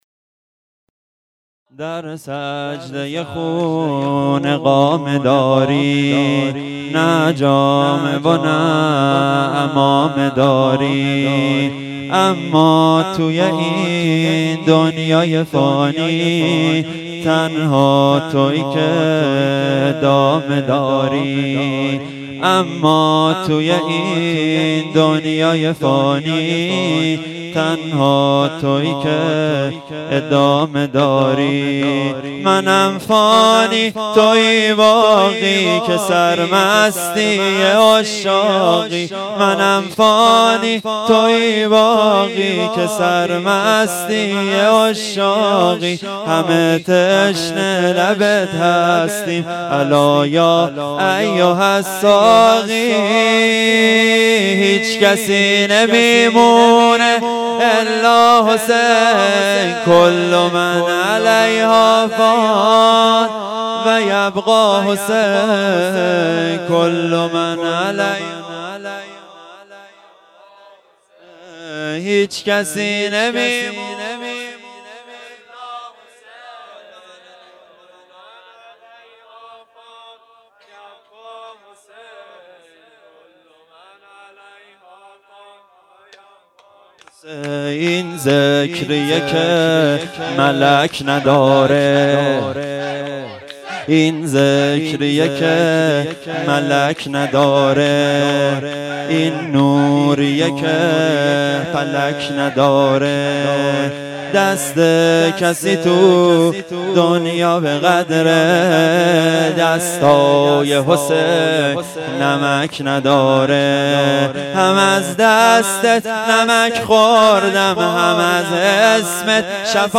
شور | در سجده خون اقامه داری
شب سوم محرم ۱۴۴۴